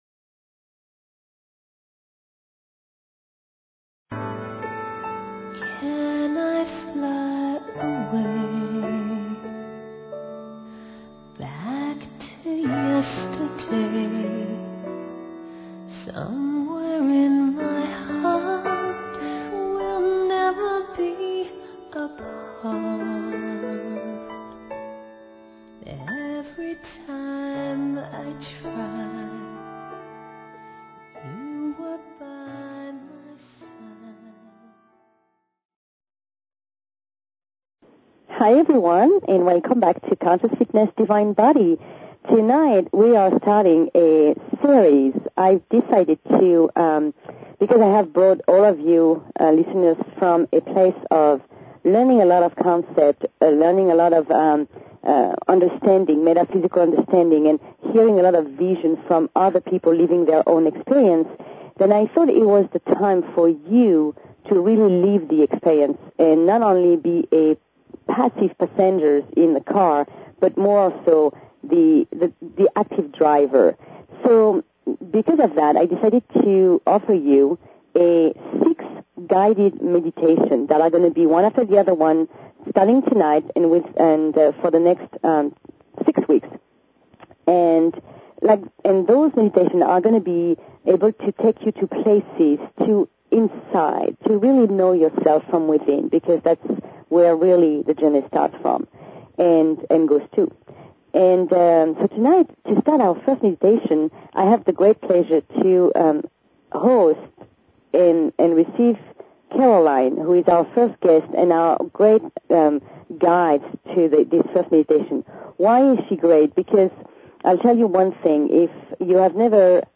Talk Show Episode, Audio Podcast, Conscious_Fitness and Courtesy of BBS Radio on , show guests , about , categorized as